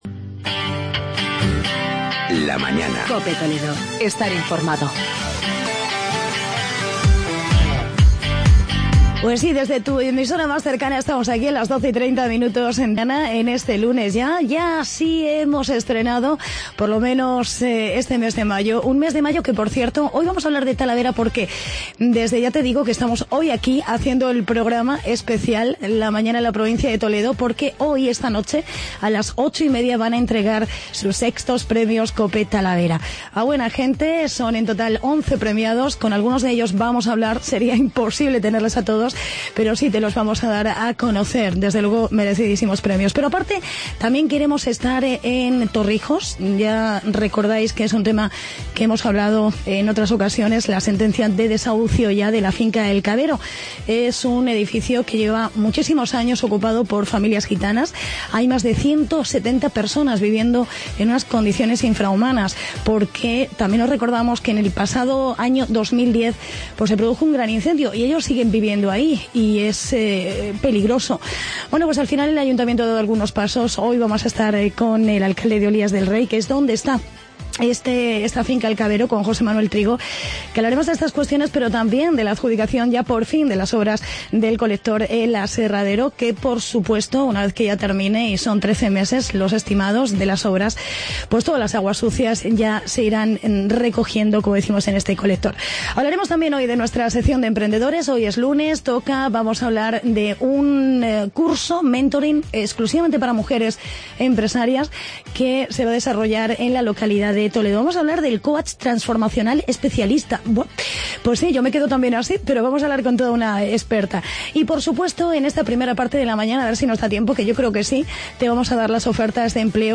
Entrevista con el alcalde de Olías del Rey